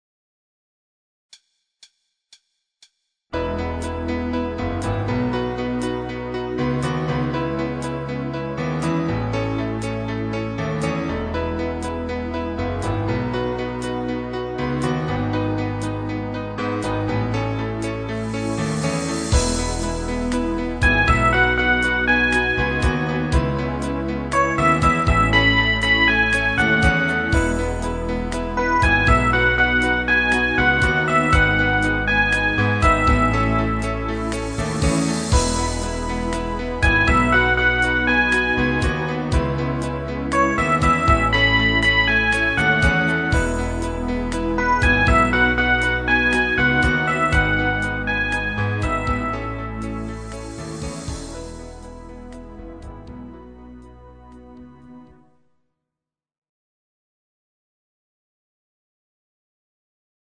Genre(s): Internat.Pop  |  Rhythmus-Style: Rockbeat